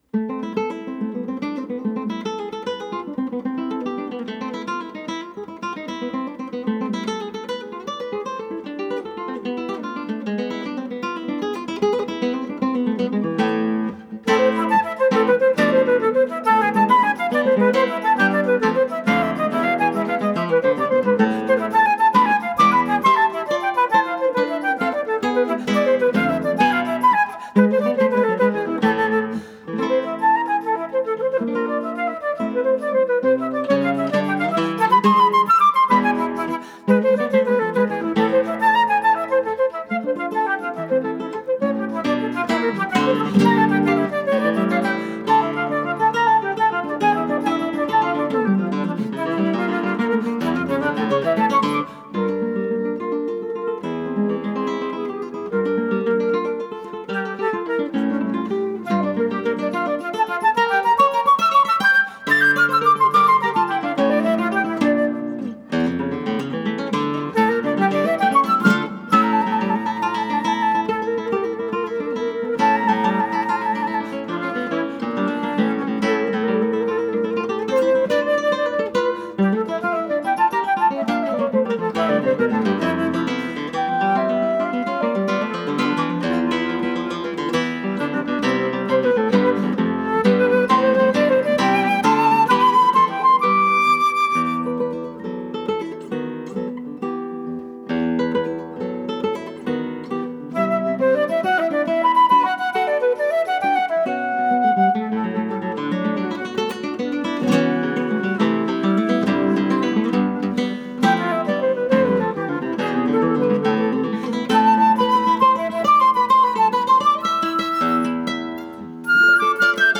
for flute and guitar
It’s super difficult at times but we made a pretty good go of it!
jezabal-fluteguit01.wav